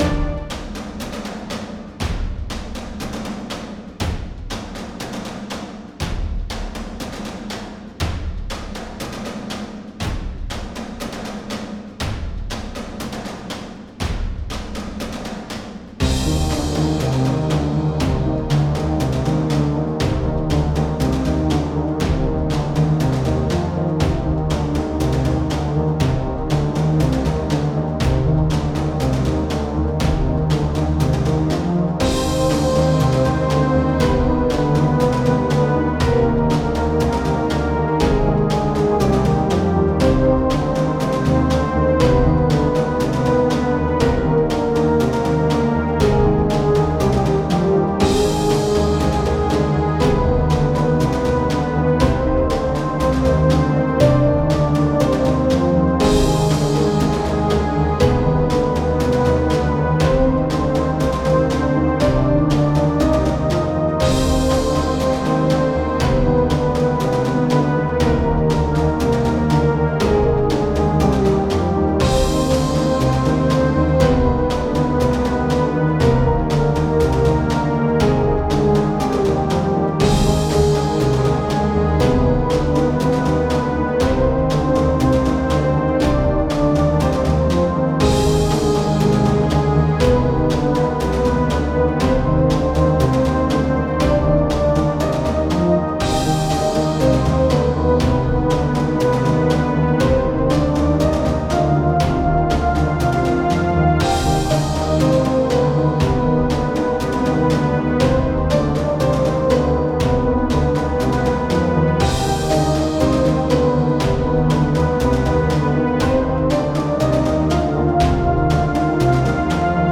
Music
synth
tribal
piano
loop